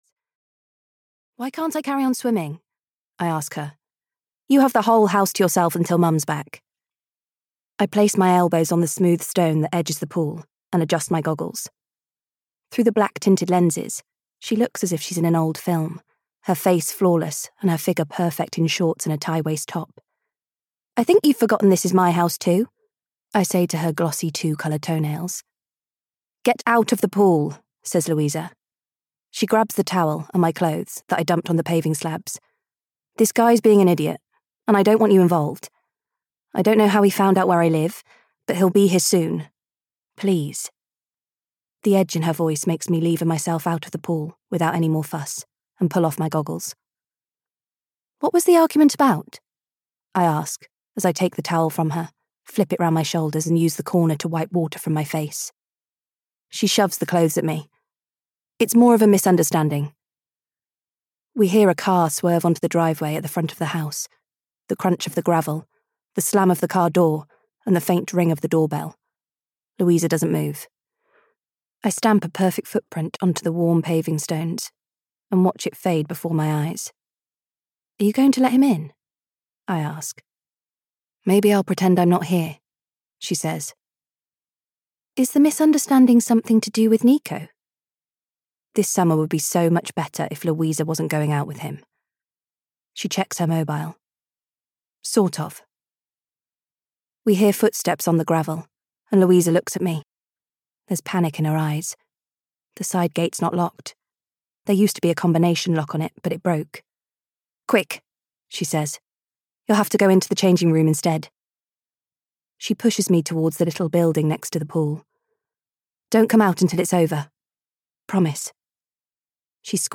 Lying About Last Summer (EN) audiokniha
Ukázka z knihy